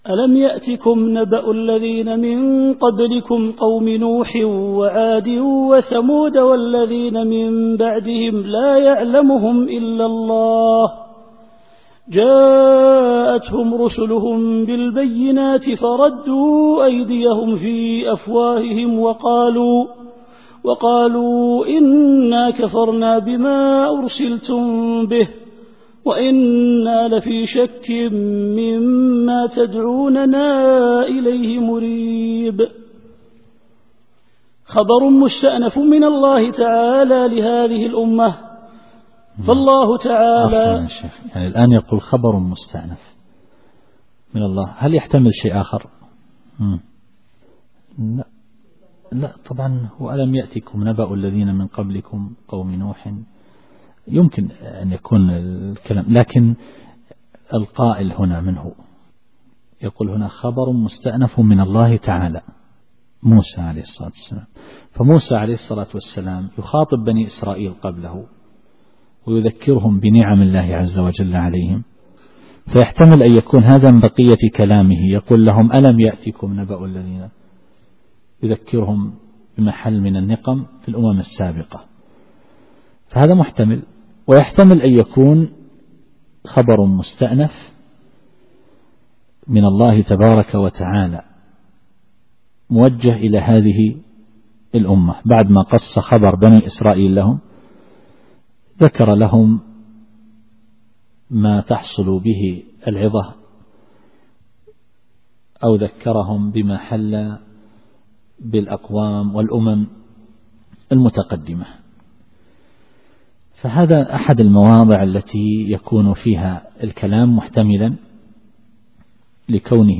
التفسير الصوتي [إبراهيم / 9]